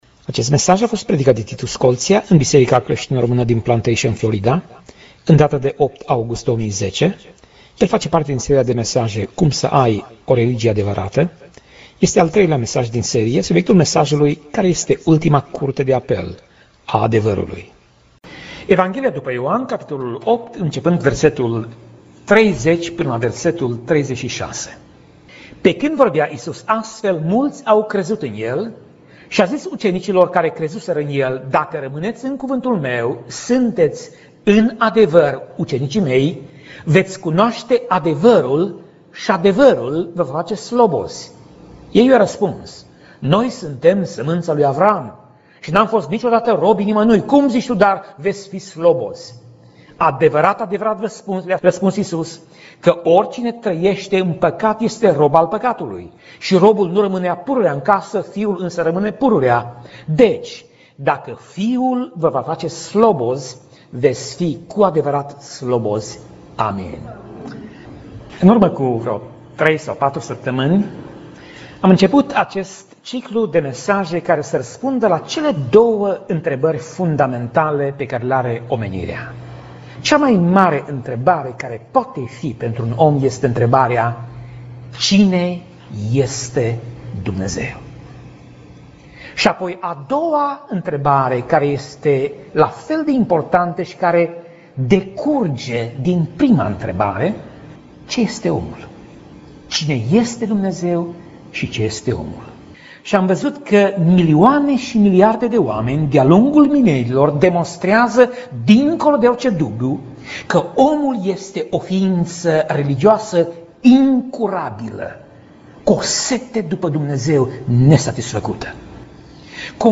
Pasaj Biblie: Ioan 8:30 - Ioan 8:36 Tip Mesaj: Predica